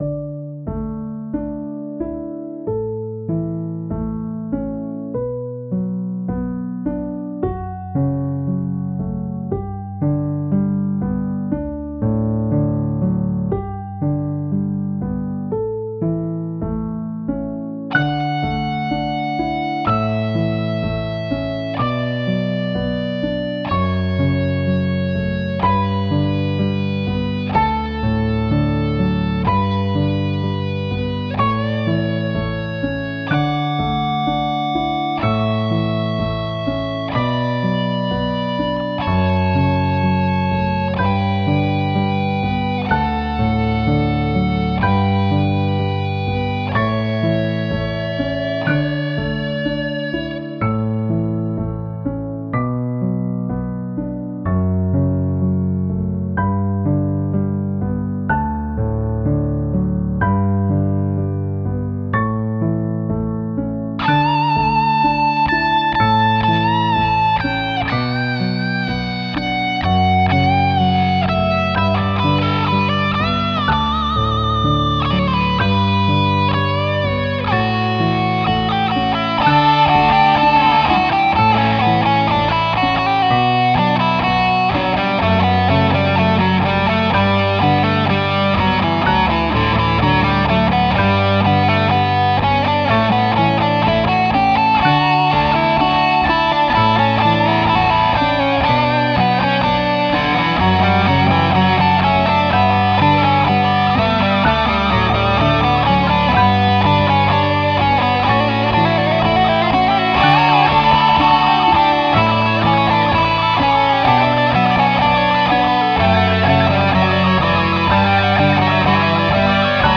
It is a progression through a period of time (such as a day), that reflects on my emotional/psychological state. Piano was sequenced in Cubasis VST. The guitars were recorded with a BC Rich Platinum Bich through a Johnson J-Station.